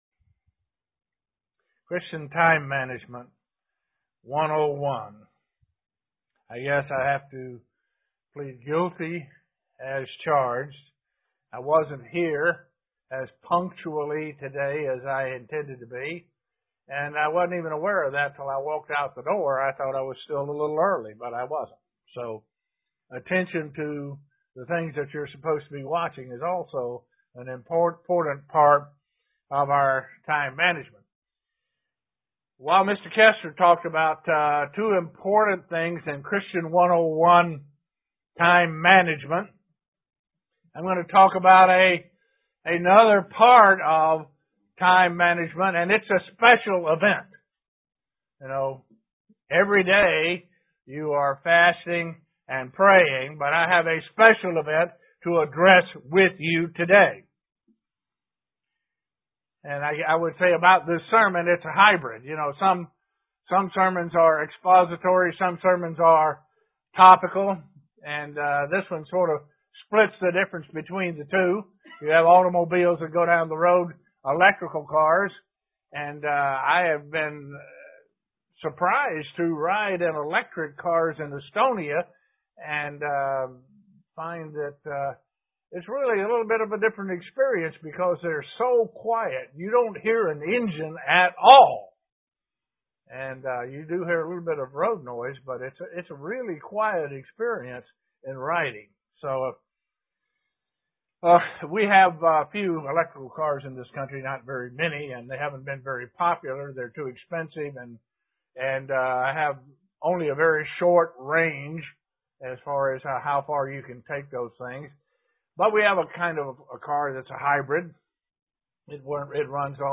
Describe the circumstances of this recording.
Given in Elmira, NY